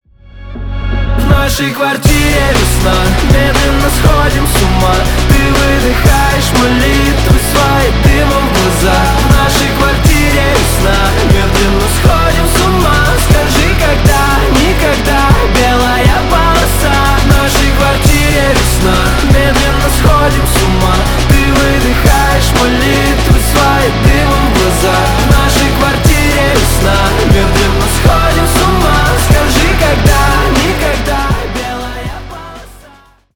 Рок Металл
грустные